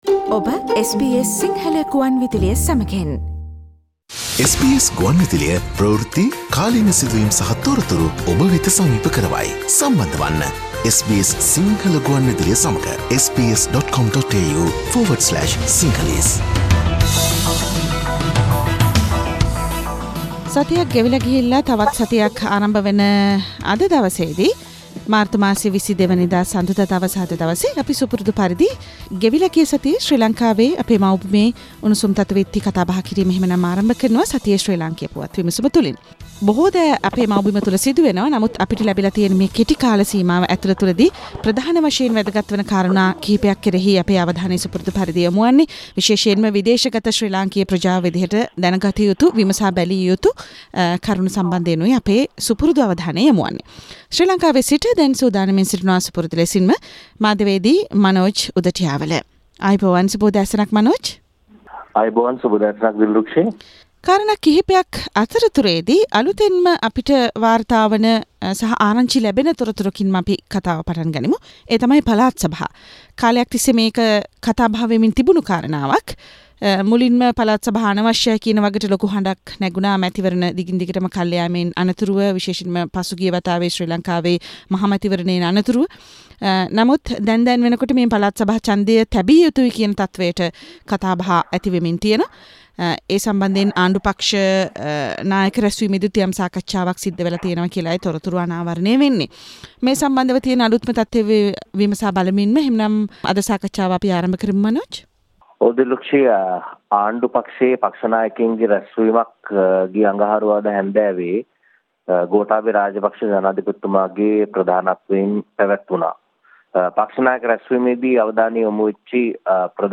Sri Lankan news wrap... Source: SBS Sinhala